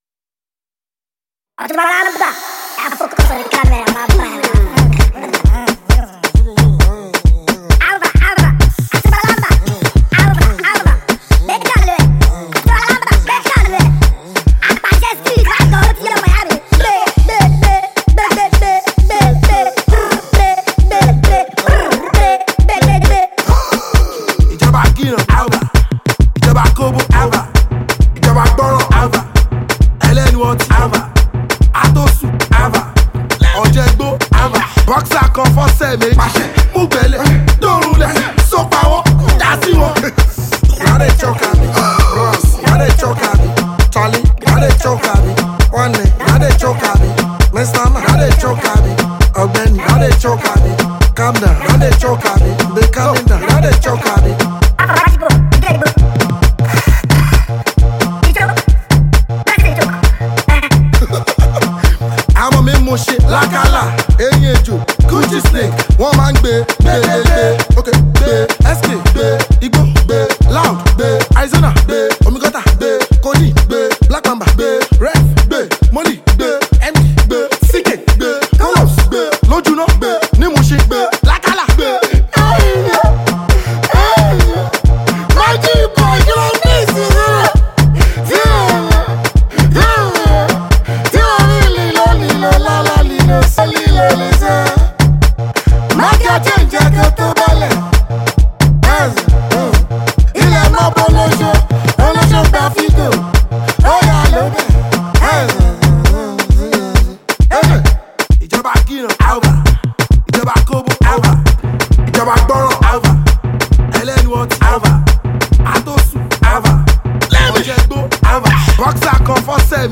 Nigerian street-hop singer